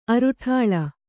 ♪ aruṭhāḷa;